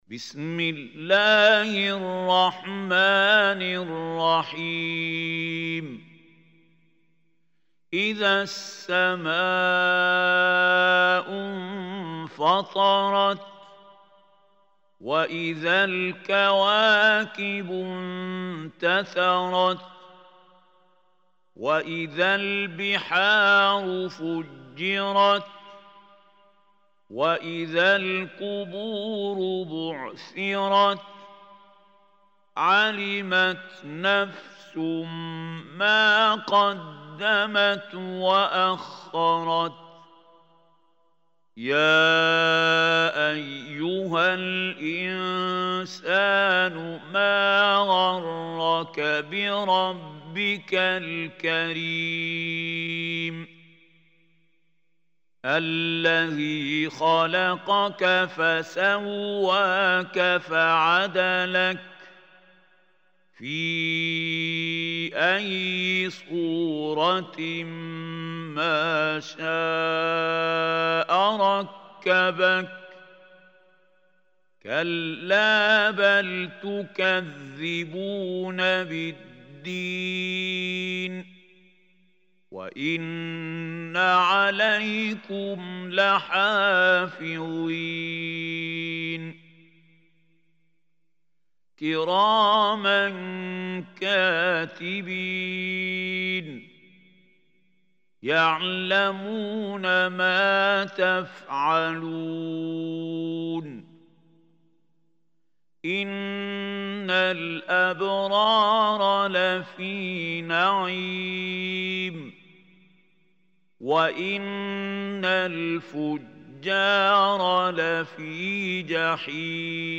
Surah Infitar Recitation by Mahmoud Khalil Hussary
Surah Infitar is 82 surah of Holy Quran. Listen or play online mp3 tilawat / recitation in Arabic in the beautiful voice of Sheikh Mahmoud Khalil Hussary.